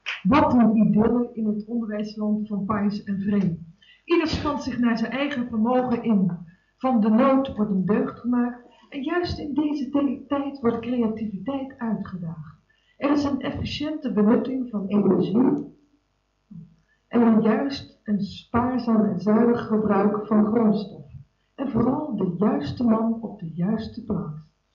Opname tijdens een Try Out met een cassetterecorder, matige kwaliteit.
Voice over